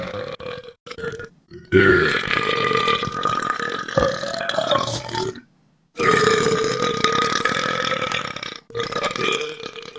Звук рыгания:
burping2.wav